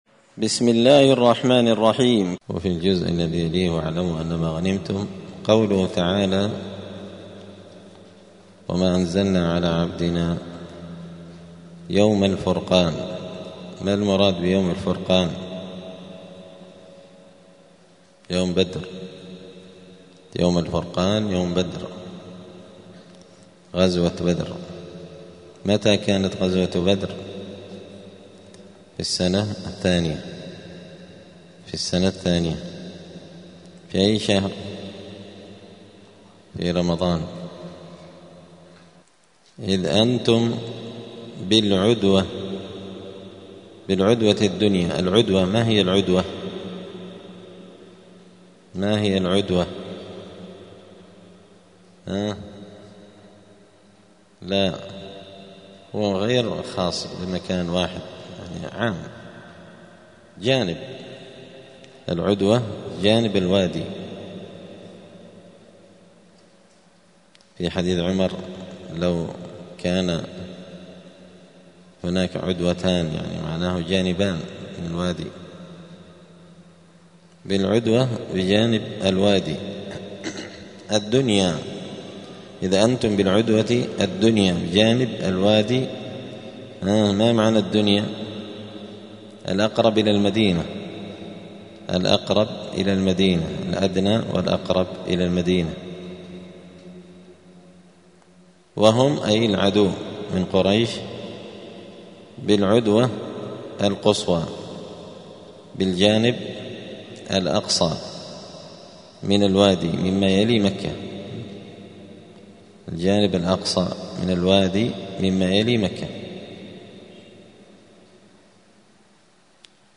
مذاكرة لغريب القرآن في رمضان – الدرس التاسع (9) : غريب الجزء العاشر.
دار الحديث السلفية بمسجد الفرقان قشن المهرة اليمن